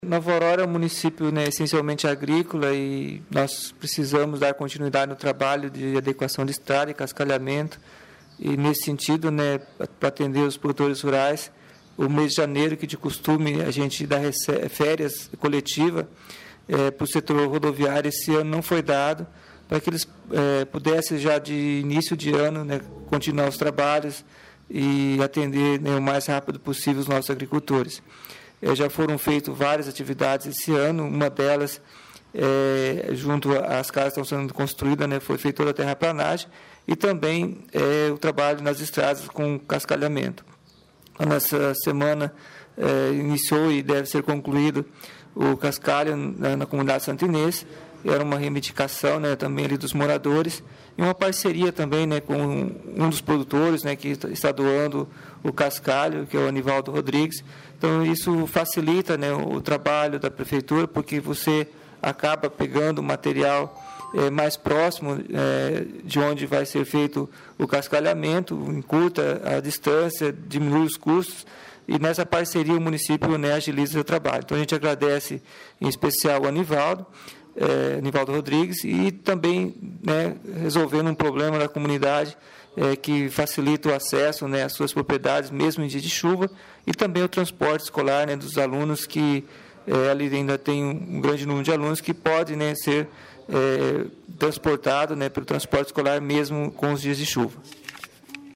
Entrevista Prefeito Pedro Leandro Neto